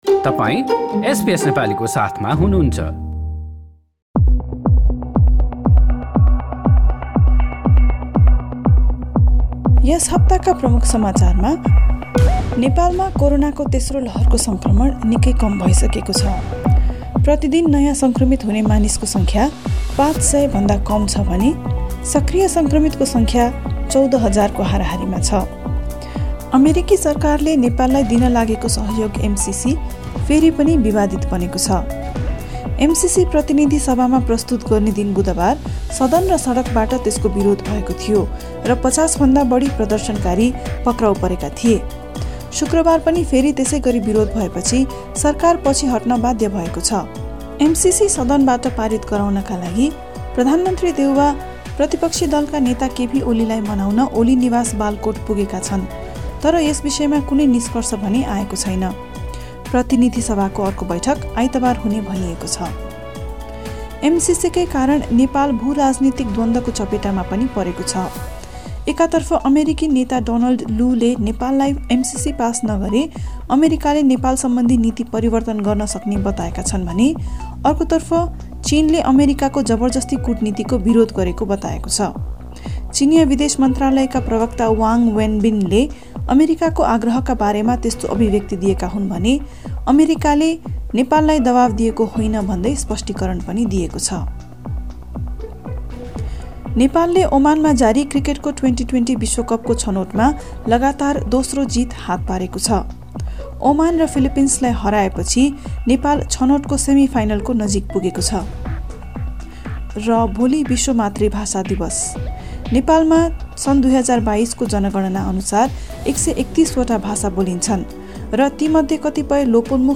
कुराकानीका साथै पछिल्लो सात दिनका प्रमुख समाचार सुन्नुहोस्।